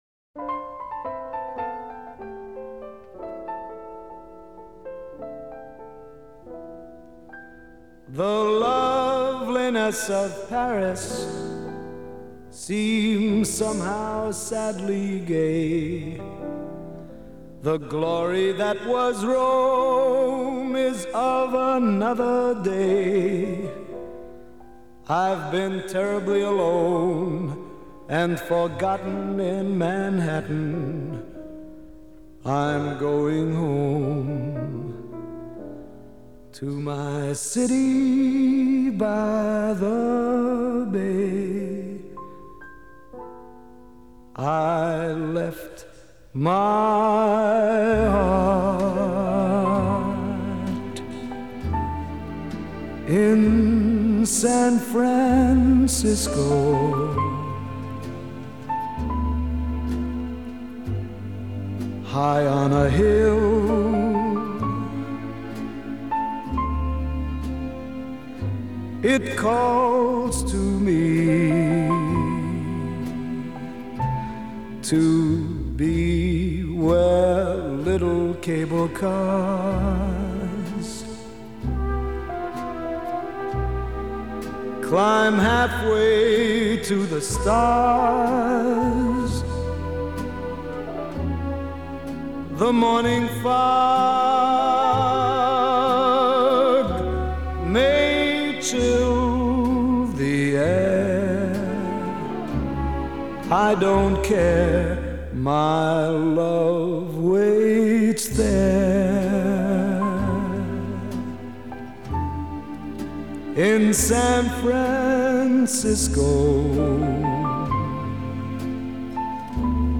以他甜醇的嗓音为半世纪以来的流行及爵士乐坛挥洒出无数充满浓郁深情的有声情书